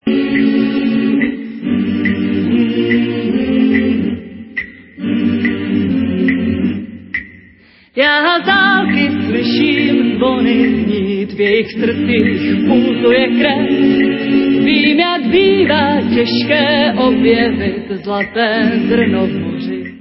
Renesančně laděnými autorskými písněmi